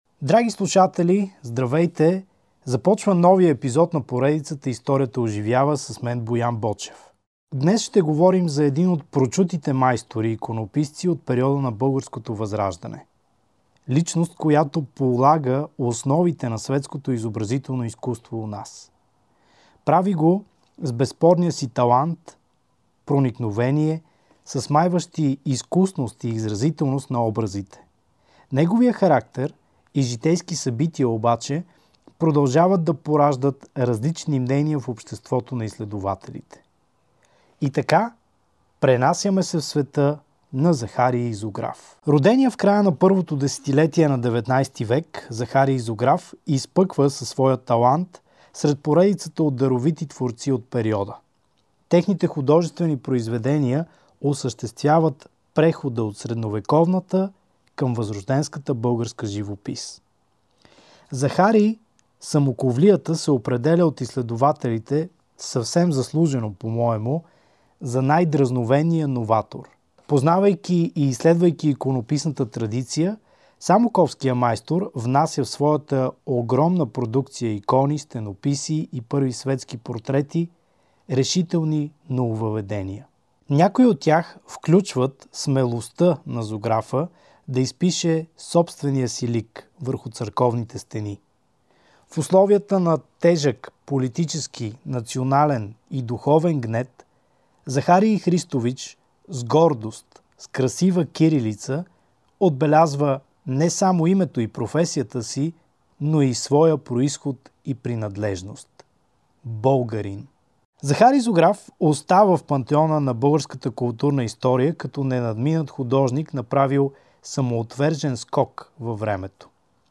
Всяка събота от 13 до 16 часа по Радио София